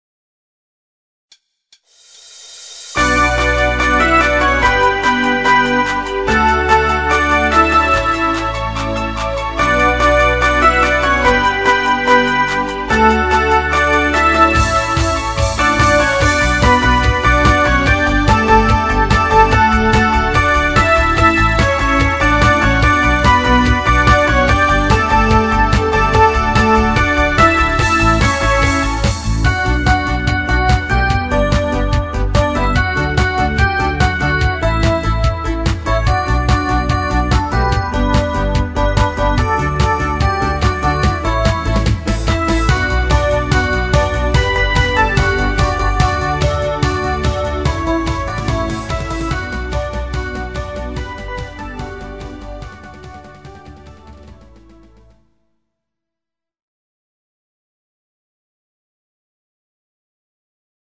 Genre(s): Deutschpop  Partyhits  |  Rhythmus-Style: Discofox